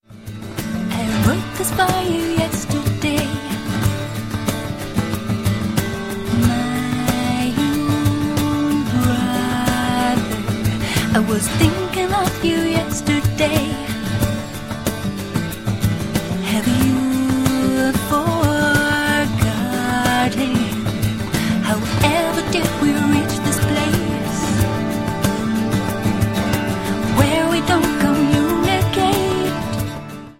• Sachgebiet: Celtic